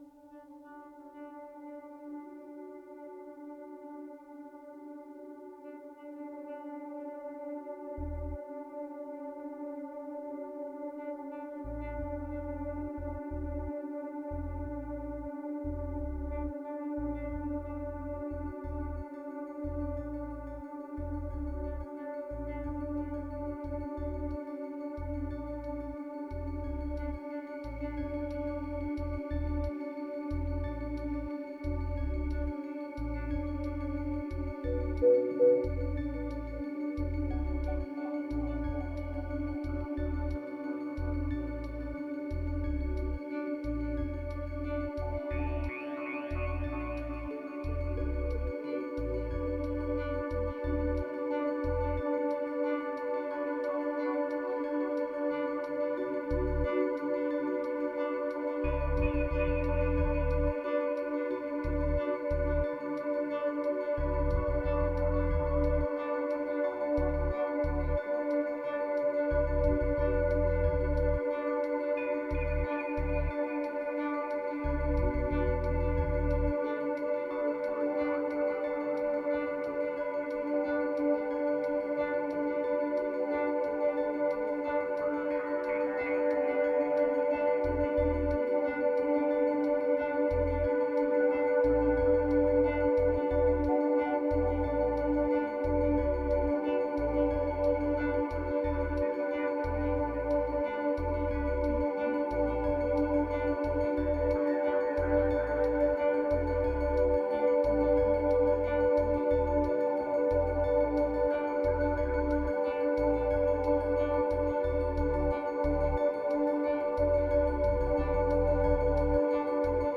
2532📈 - 84%🤔 - 90BPM🔊 - 2017-06-03📅 - 802🌟